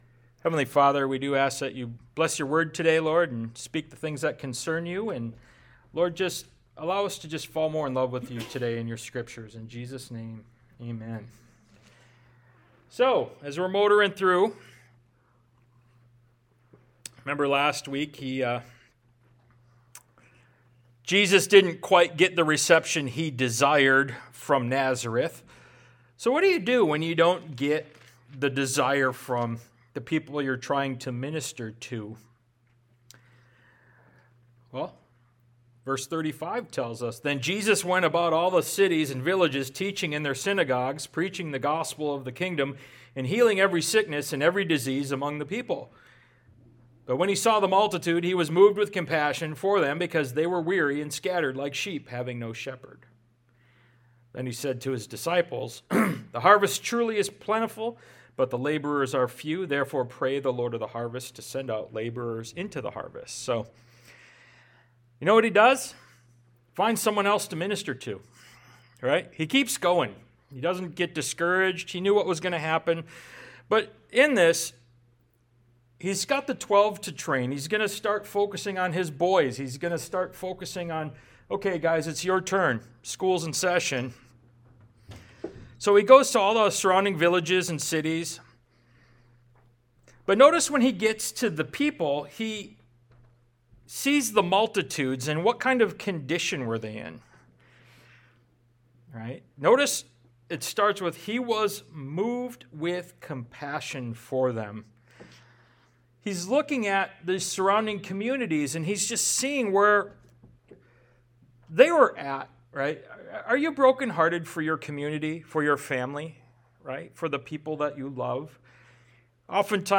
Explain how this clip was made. Ministry of Jesus Service Type: Sunday Morning « Astonished